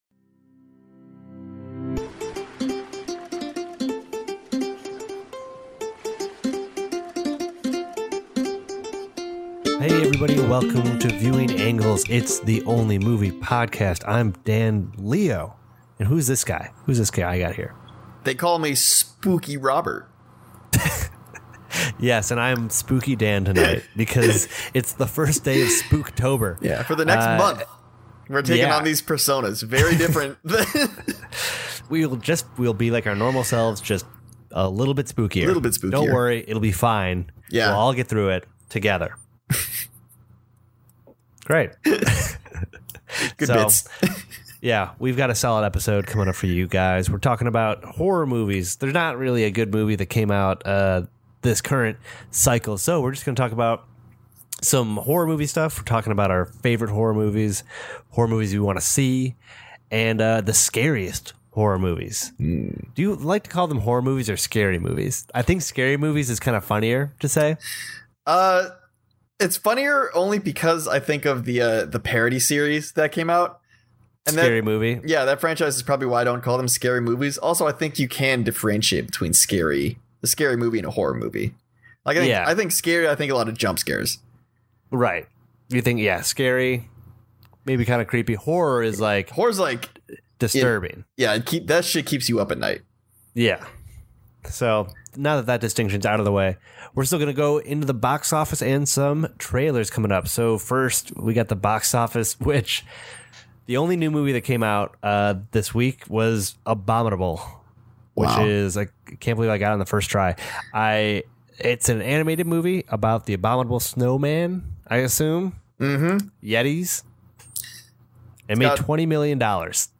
This episode has a lot of talking. We didn’t see any new movies, so instead we talk about the Joker discourse and our favorite Halloween movies.